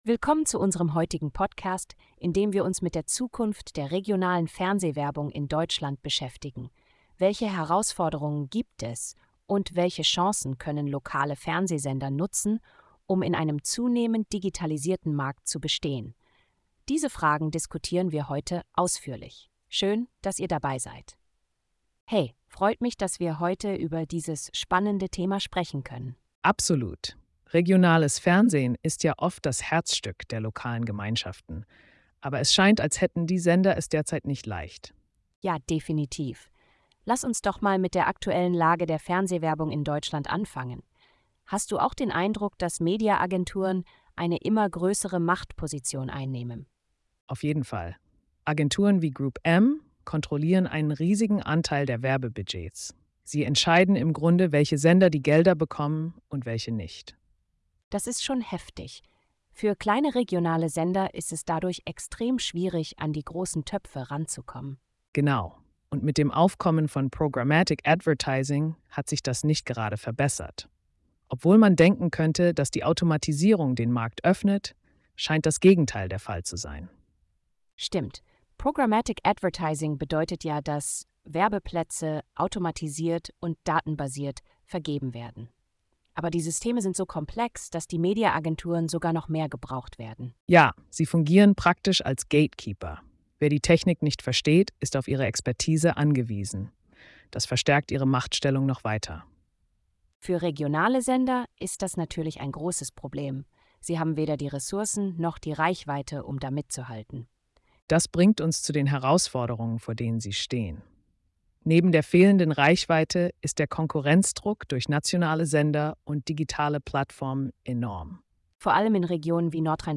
Unsere KI-Hosts diskutieren über regionale TV-Werbung – Herausforderungen und Chancen im digitalen Wandel.